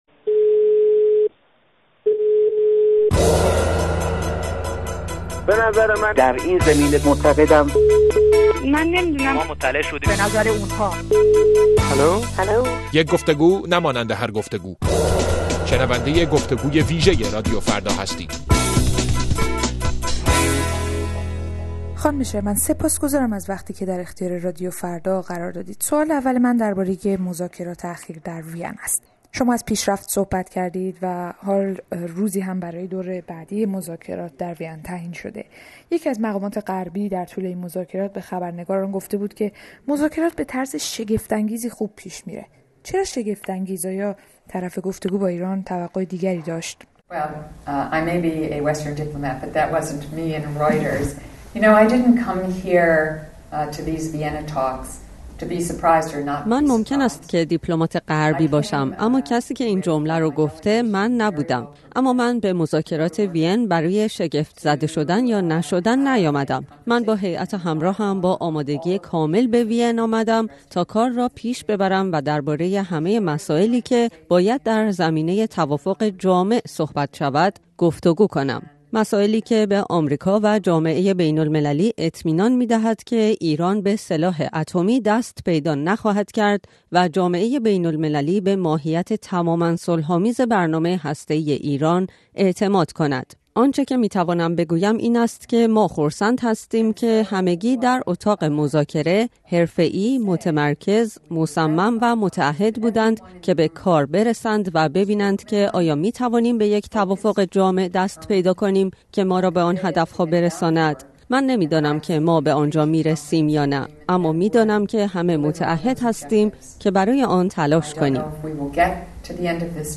گفت‌وگوی اختصاصی رادیو فردا با وندی شرمن، معاون وزرات خارجه آمریکا